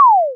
laser_sound.wav